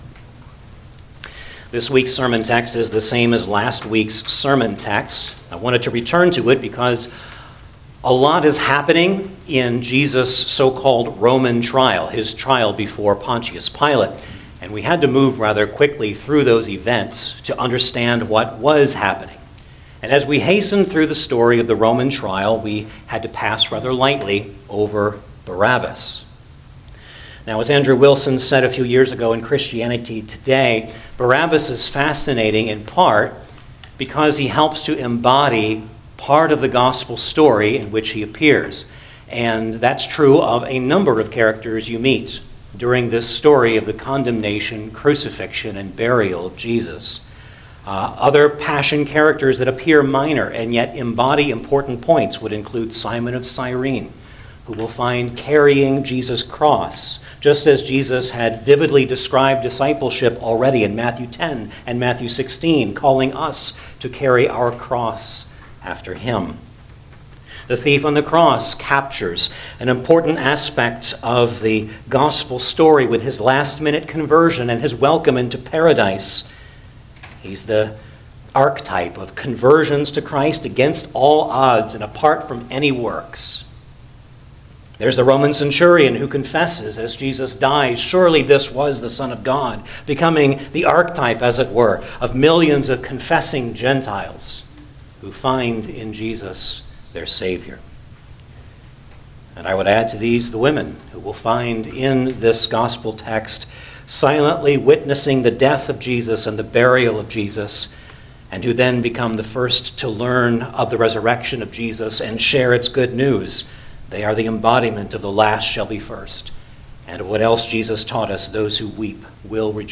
Matthew 27:11-26 Service Type: Sunday Morning Service Download the order of worship here .